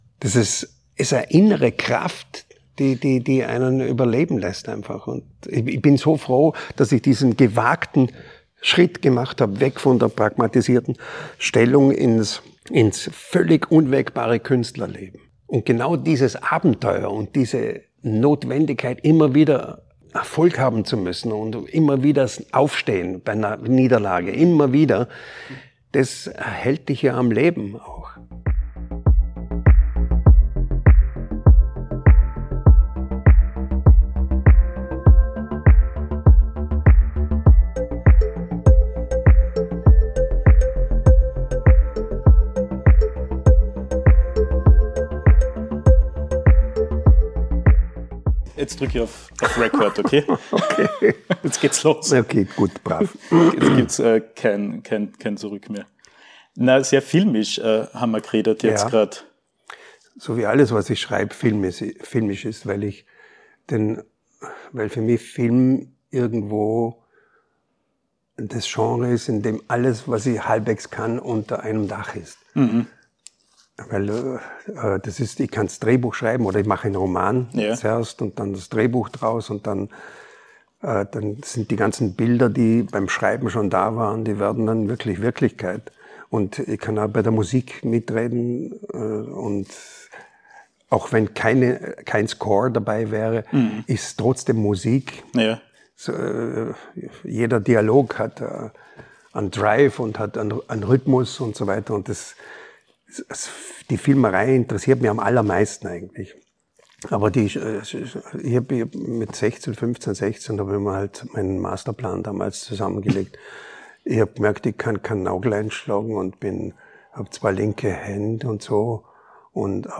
Ein Gespräch über Vergangenheit, Gegenwart und die Verantwortung für die Zukunft.